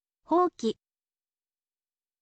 houki